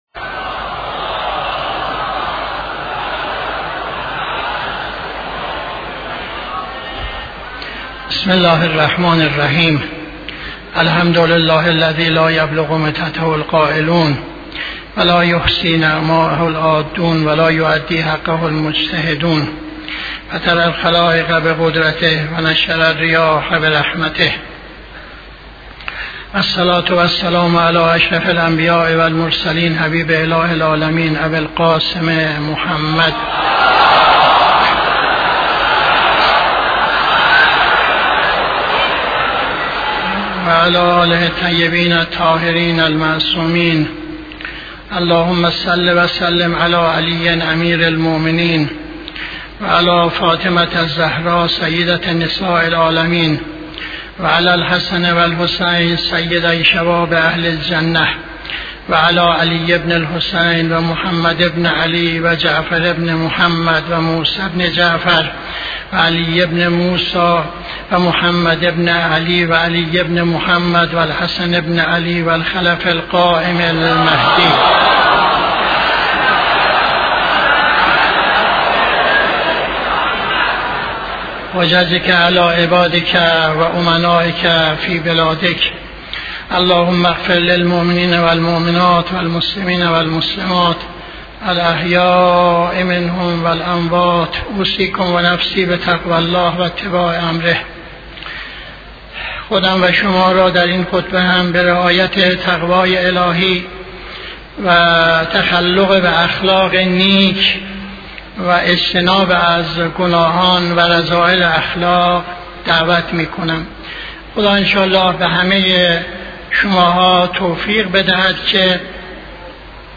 خطبه دوم نماز جمعه 20-07-80